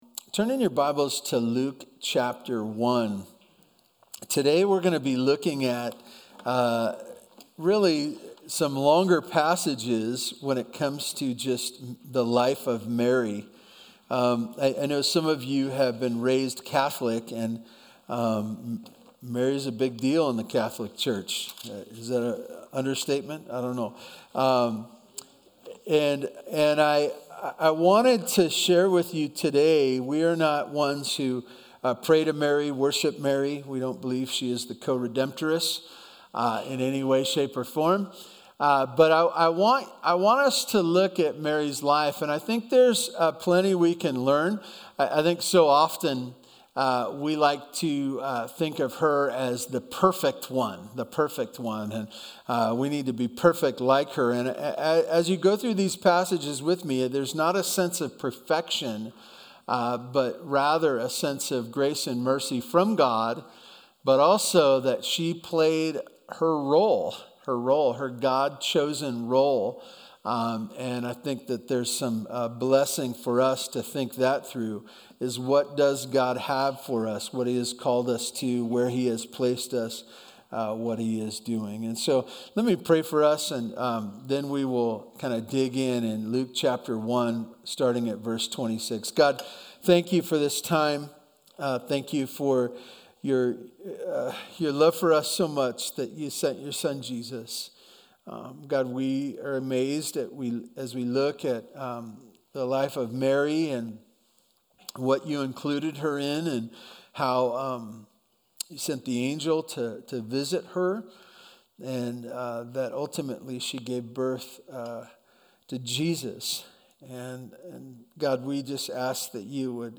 BVC Sunday Sermons